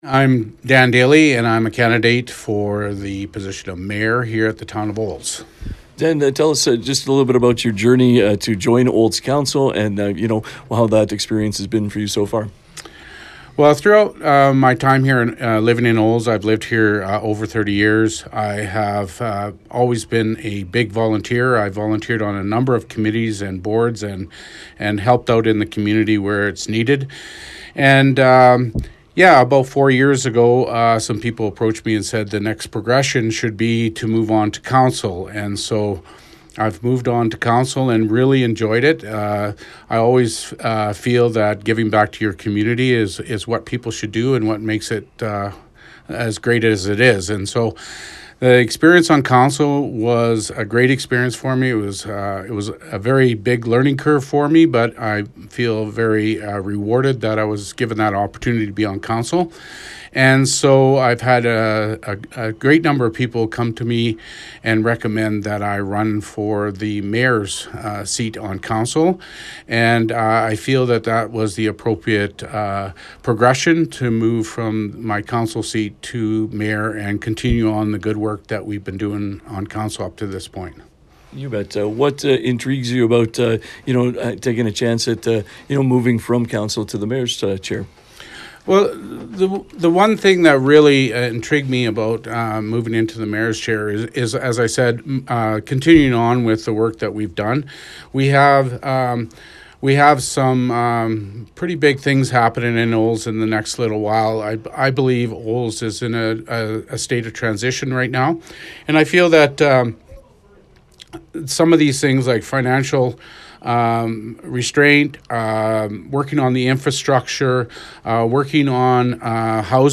Listen to 96.5 The Ranch’s conversation with Dan Daley.